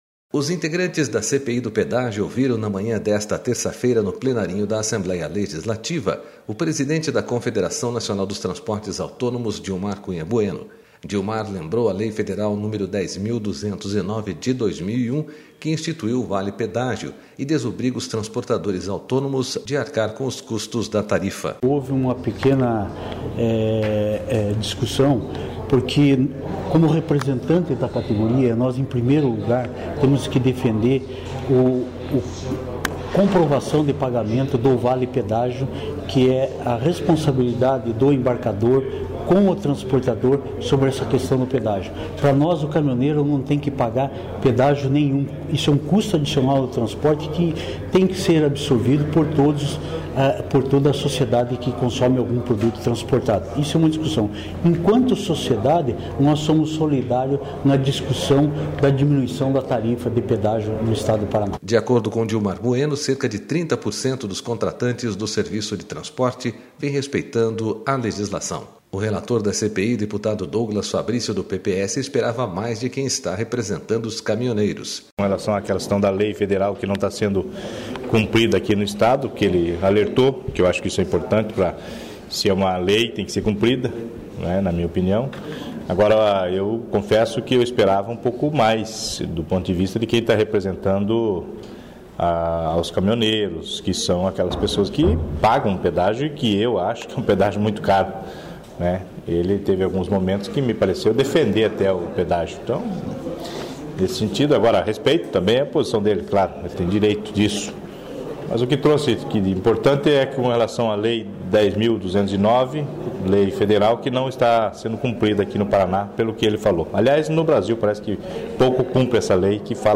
Os integrantes da CPI do Pedágio ouviram na manhã desta terça-feira, no Plenarinho da Assembleia Legislativa